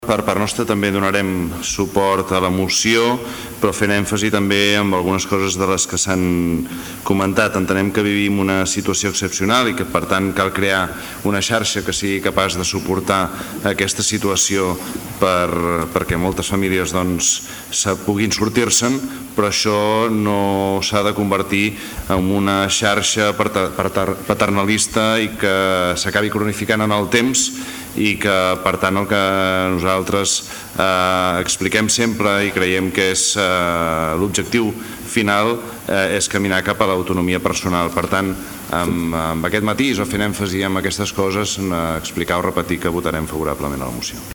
Junts per Tordera feia èmfasi en “crear una xarxa que sigui capaç de suportar aquesta situació” però que no esdevingui una xarxa “paternalista i crònica en el temps” sinó amb l’objectiu de caminar cap a l’autonomia del benestar personal. El regidor Josep Llorens mostrava el vot favorable per part del partit.